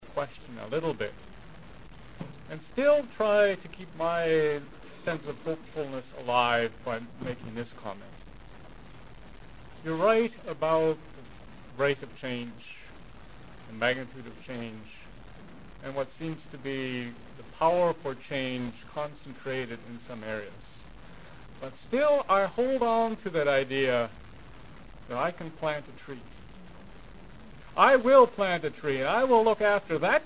Location: Sven Ericksen’s Family Restaurant (lower level)